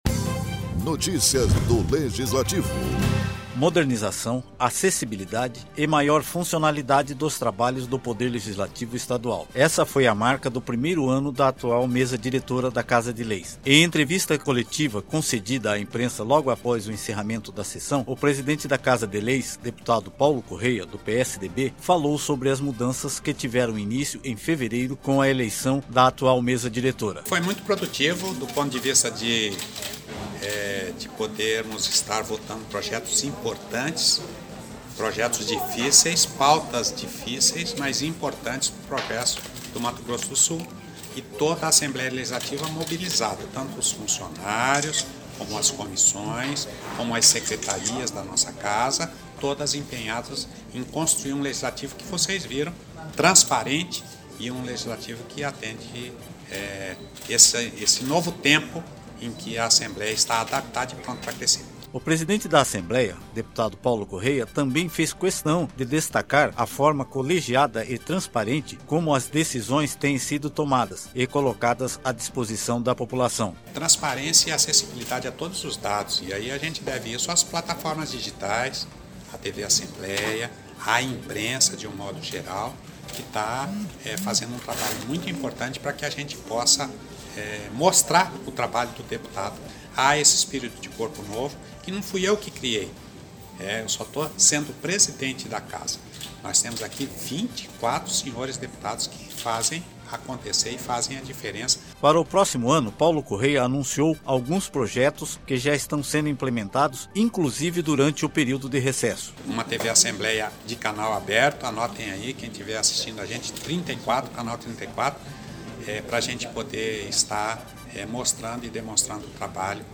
Modernização, acessibilidade e maior funcionalidade dos trabalhos do Poder Legislativo Estadual, essa foi a marca do primeiro ano da atual Mesa Diretora da Casa de Leis . Em entrevista coletiva concedida à imprensa logo após o encerramento da sessão, o presidente da Assembleia Legislativa, deputado Paulo Corrêa, do PSDB falou sobre as mudanças que tiveram inicio em fevereiro com a eleição da atual Mesa Diretora.